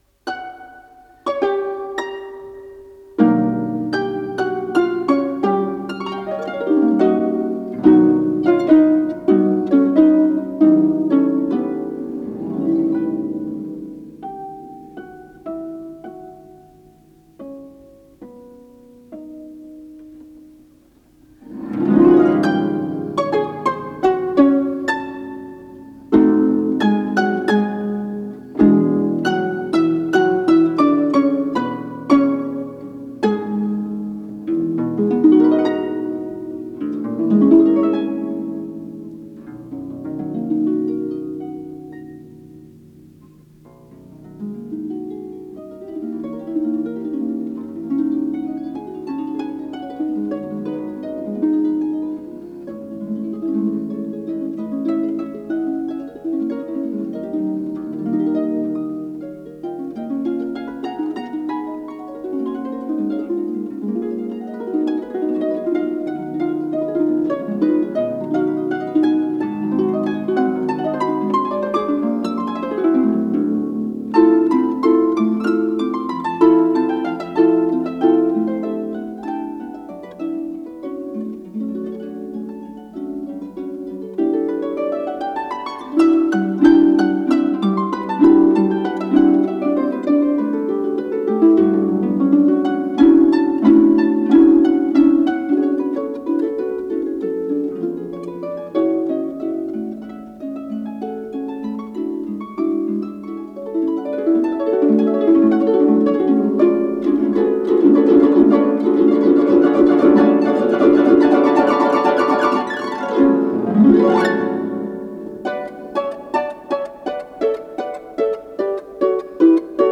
До бемоль мажор.
арфа